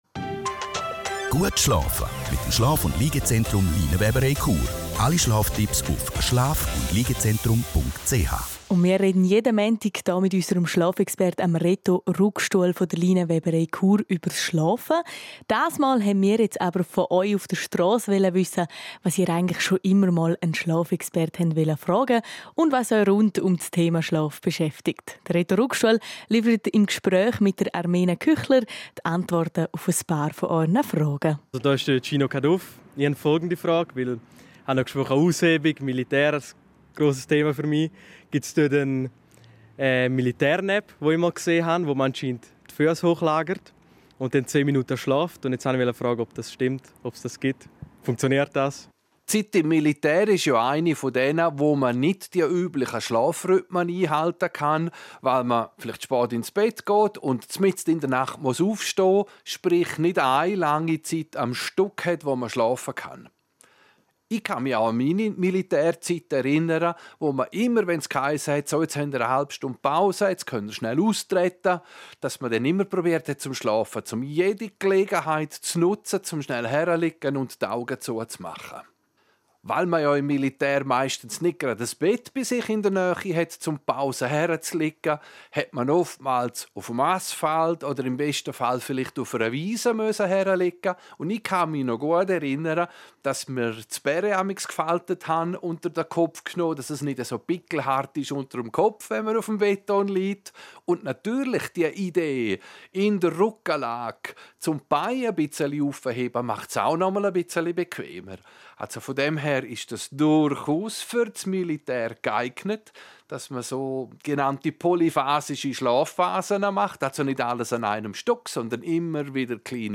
Ein Schlafexperte erläutert die Vorteile des Hochlagerns der Beine.